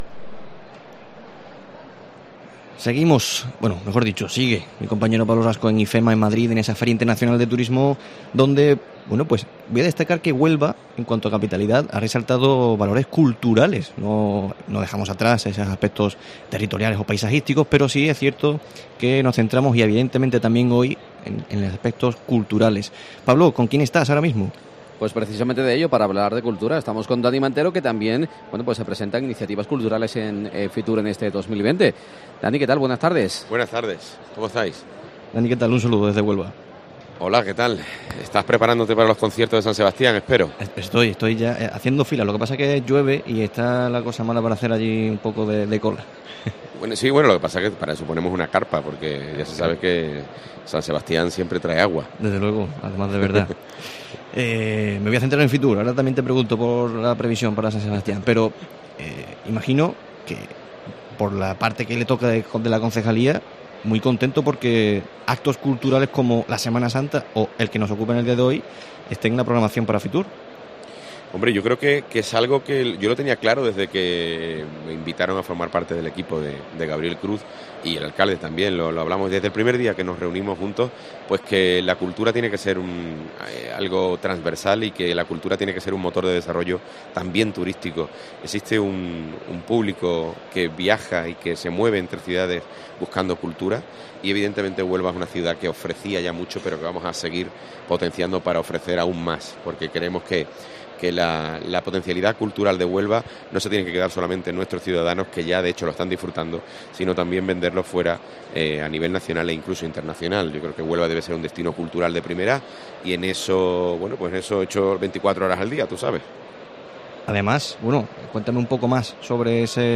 Daniel Mantero, concejal de Cultura del Ayuntamiento de Huelva, atiende a COPE Huelva desde FITUR para informar sobre las propuestas culturales onubenses.